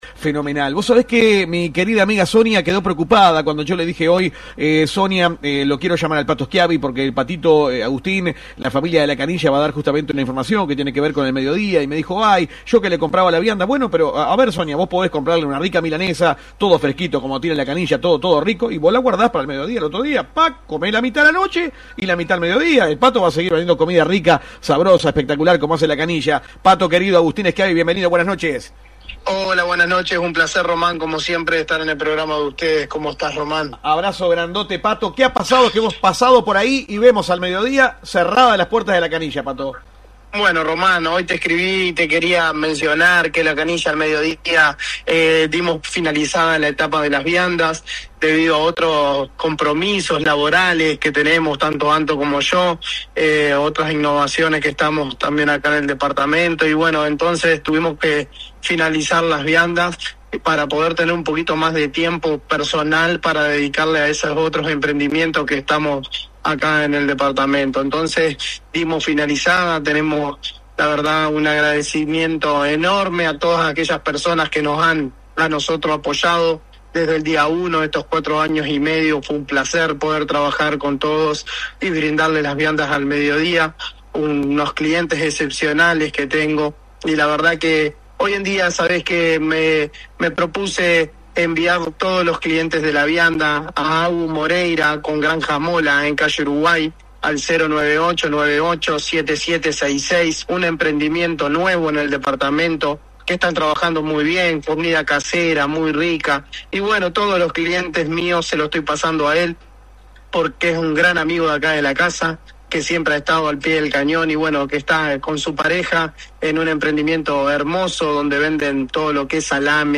Comunicación telefónica con